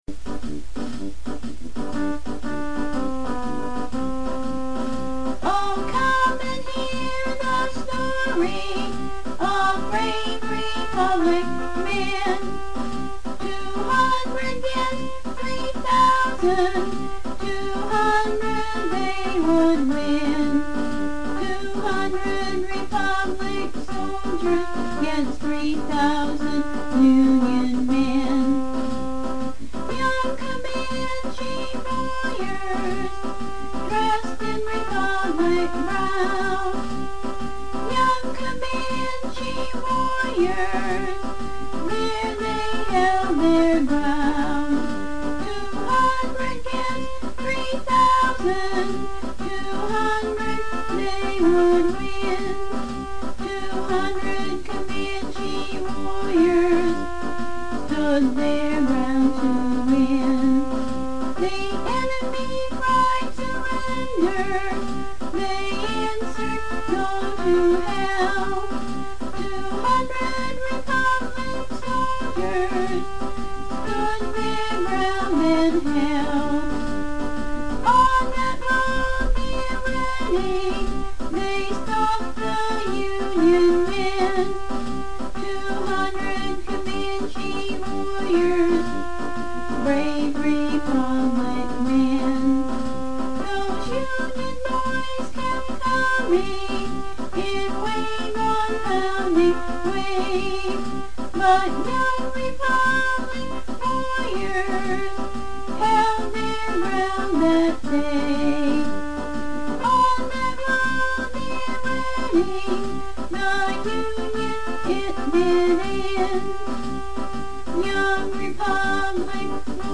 Below is an audio demo and the lyrics.
sing This Song And
Accompany Herself On Her Keyboard